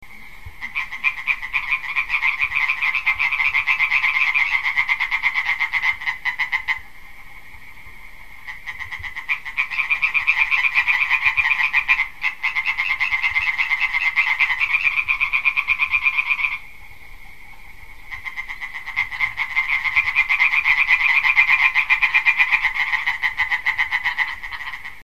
hyla_arborea.mp3